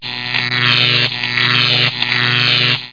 00032_Sound_choque.mp3